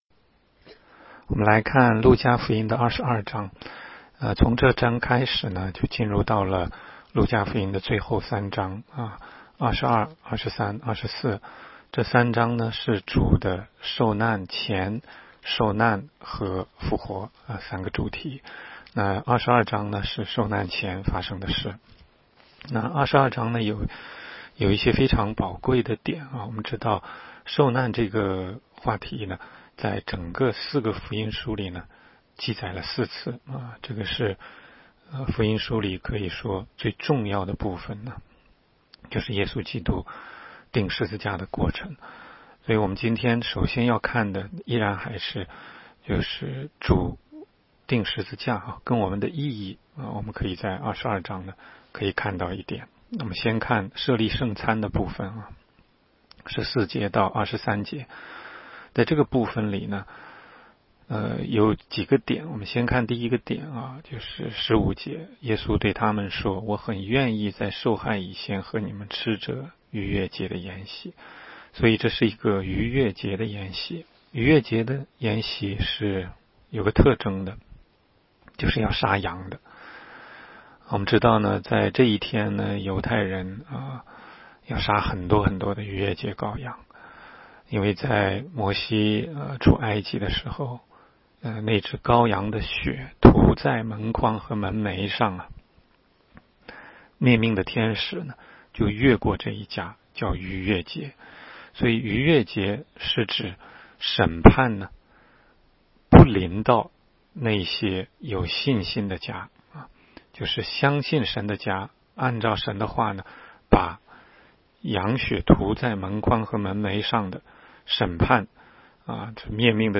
全中文讲道与查经